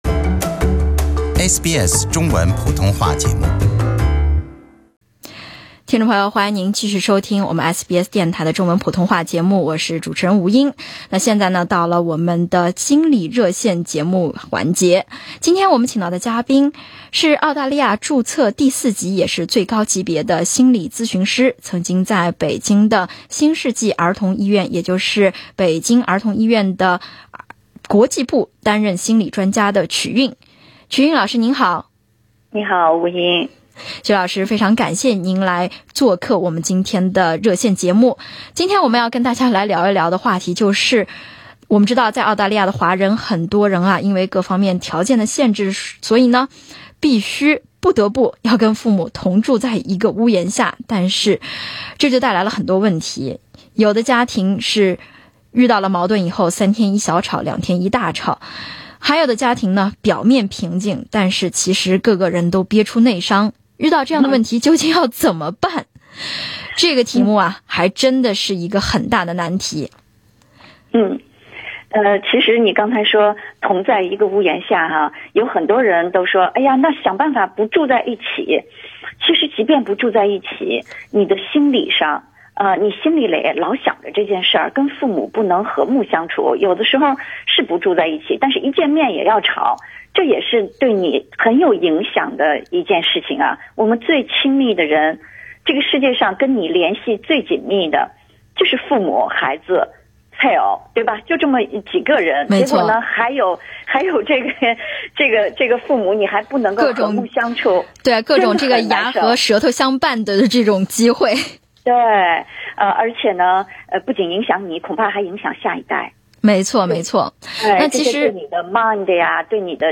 24:21 Source: SBS SBS 普通话电台 View Podcast Series Follow and Subscribe Apple Podcasts YouTube Spotify Download (44.61MB) Download the SBS Audio app Available on iOS and Android 家里老人总在餐桌上暴怒，说不想吃饭不想活该怎么办？